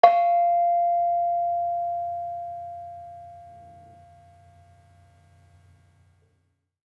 Bonang-F4-f.wav